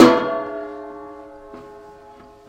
描述：这些是由锅，平底锅等炊具记录的声音......它们由金属和木棒制成。用Stagg PGT40 麦克风，Digidesignmbox（原始） 小型隔离室中的波形记录器。文件是 单声道，16,44khz.Processed with wave editor.Pack，设置“syd”。
Tag: 打击乐 SFX